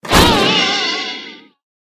wood_joint_break_01.ogg